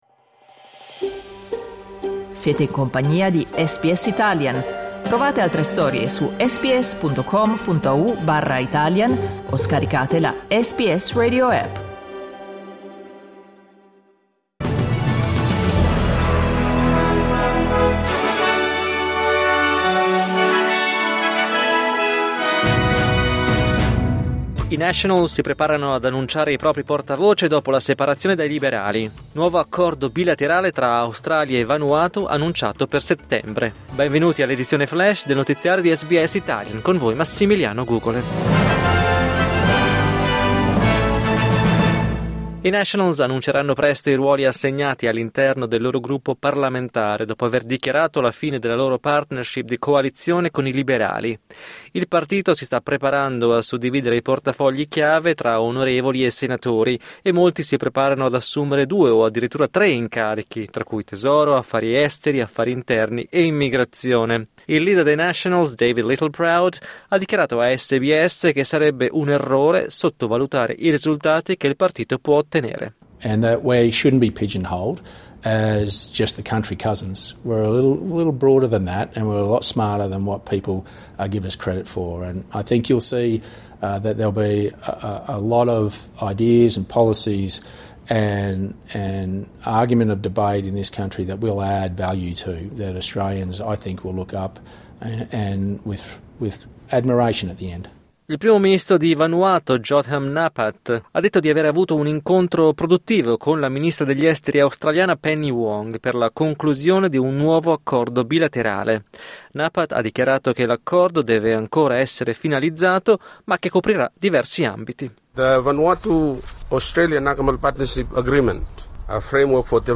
News flash giovedì 22 maggio 2025